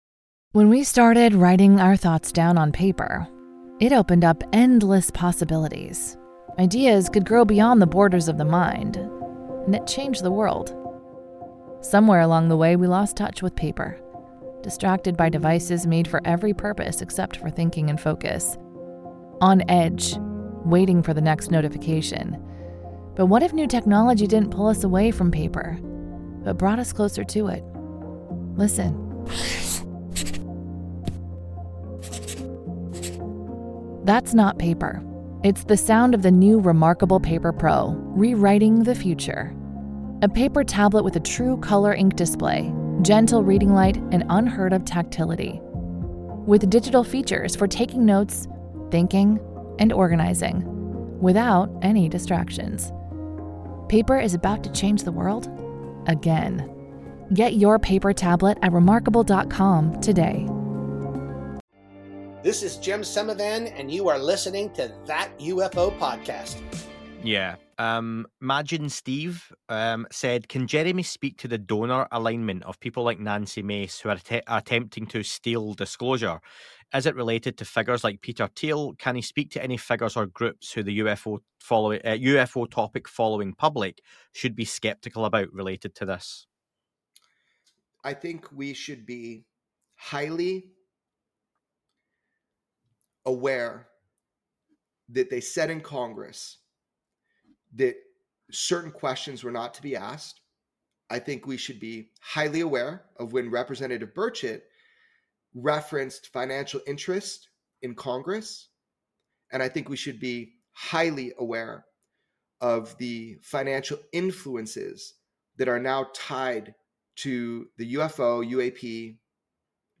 I am joined by documentary filmmaker, researcher and co-host of 'Weaponized' podcast, Jeremy Corbell to discuss;